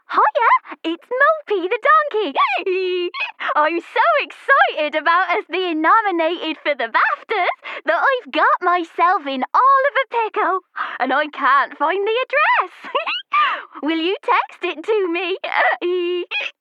Young, Bright, British Female Voice Over Talent
Female
English (British), English (Neutral - Mid Trans Atlantic)
My voice is young, bright, British and enthustiastic. I have a clear speaking sound with a neutral/RP British Accent.
Character / Cartoon